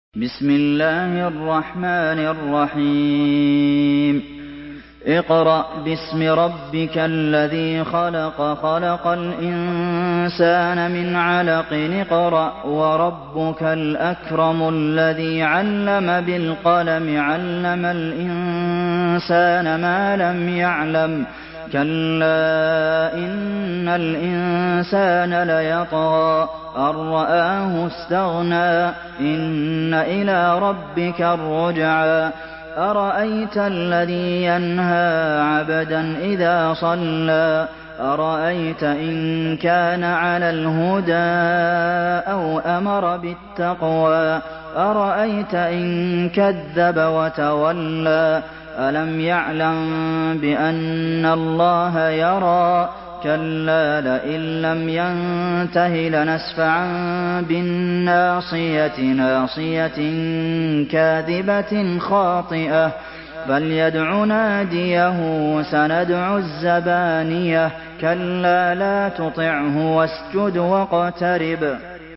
Surah Al-Alaq MP3 by Abdulmohsen Al Qasim in Hafs An Asim narration.
Murattal